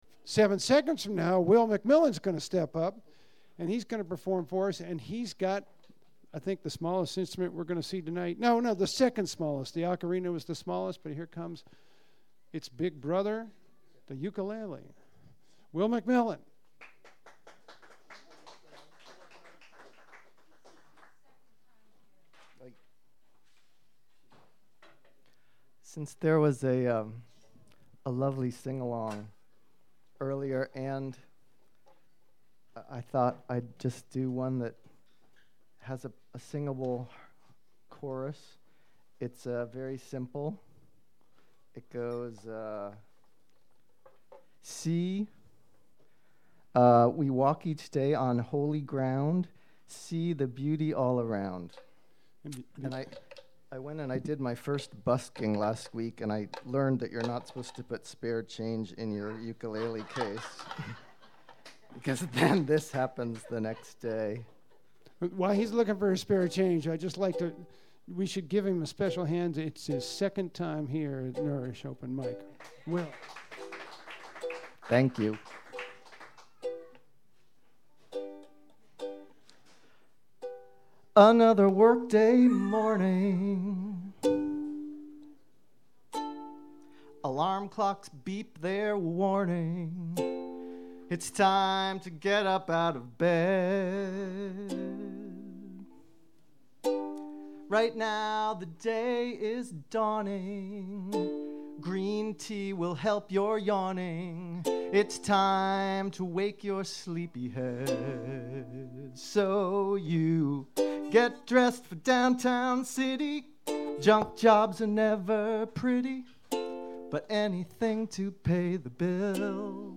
[Knowledge Domain Interpretation]   Nourish Restaurant Open Mike Private Files
Files with a title starting raw or Untitled have only been track-level volume adjusted and are not joined, clipped, equalized nor edited.